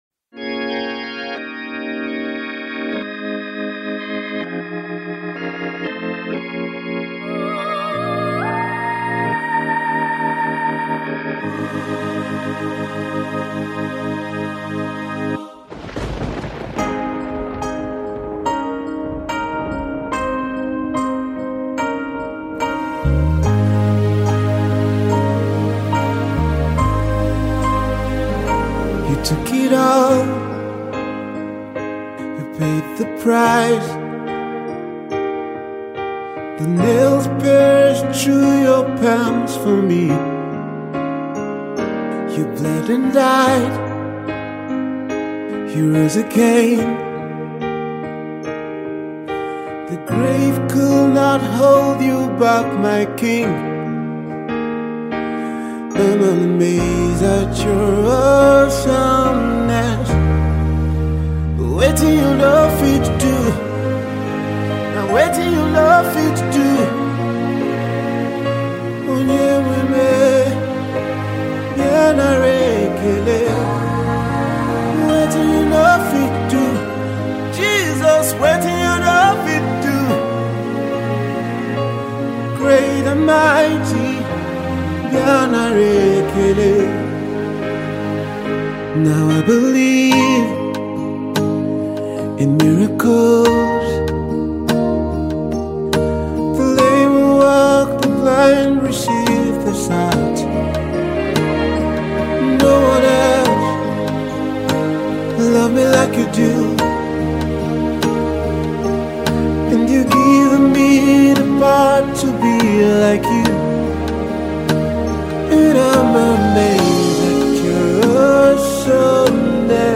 This sort of Gospel tune is on an extraordinary level.